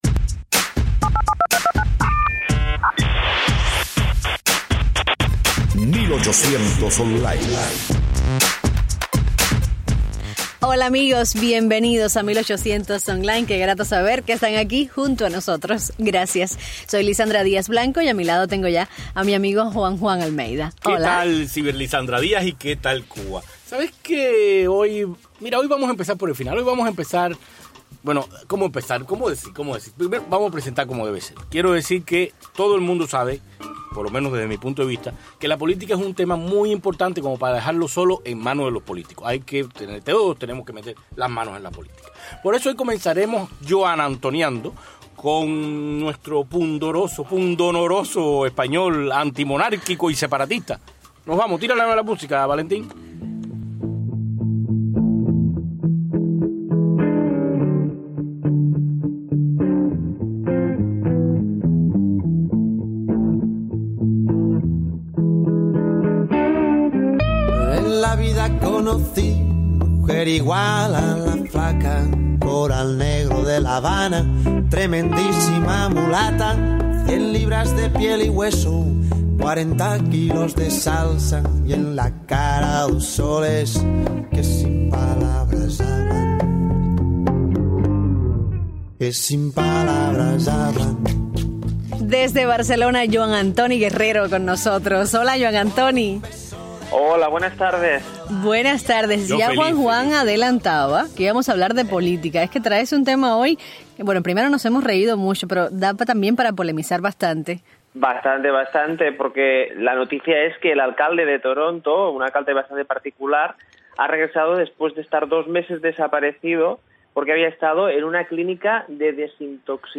También en el programa y en vivo desde el estudio, Julio Martinez, ex alcalde de Hialeah y promotor de boxeo. Dejo saber como era Hialeah en el pasado y como evitó los problemas políticos que existen hoy en día. También dejo saber como andan los boxeadores cubanos y que se puede esperar de ellos en el futuro.